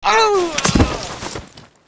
带人声的死亡倒地zth070518.wav
通用动作/01人物/02普通动作类/带人声的死亡倒地zth070518.wav